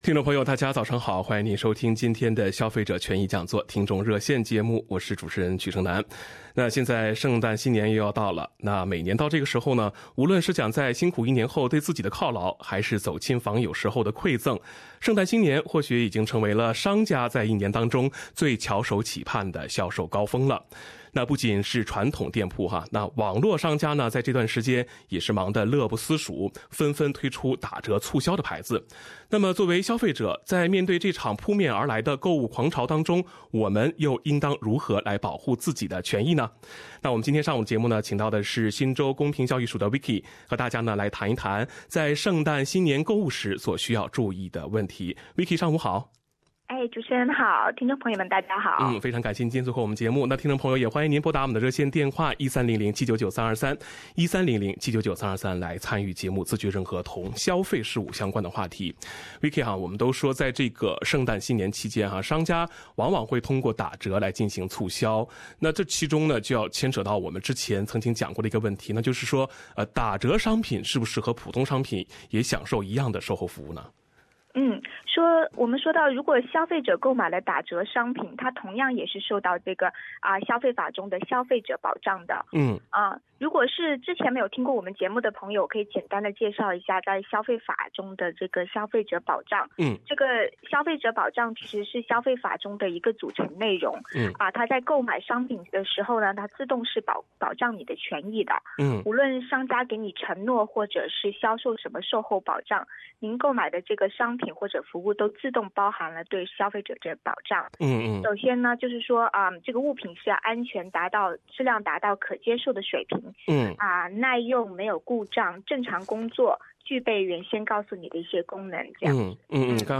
消费者权益听众热线 - 圣诞打折购物，你准备好了吗？